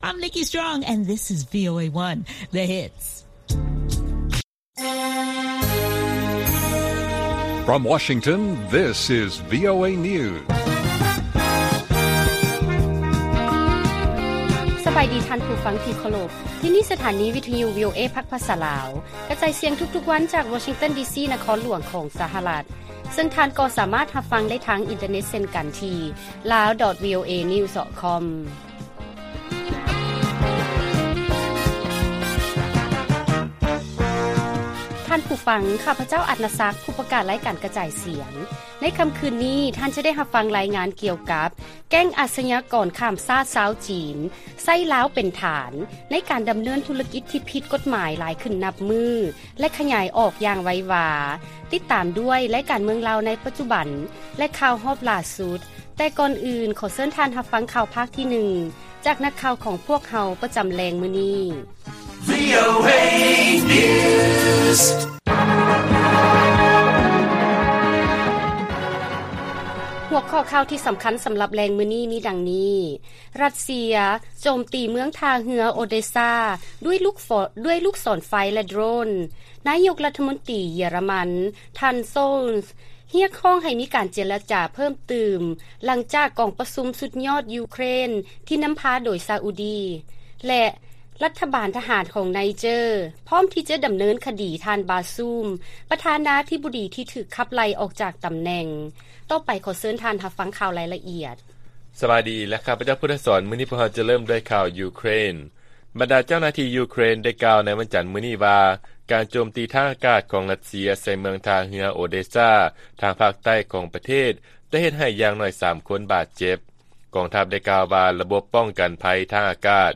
ລາຍການກະຈາຍສຽງຂອງວີໂອເອ ລາວ: ຣັດເຊຍ ໂຈມຕີເມືອງທ່າເຮືອ ໂອເດຊາ ດ້ວຍລູກສອນໄຟ ແລະ ໂດຣນ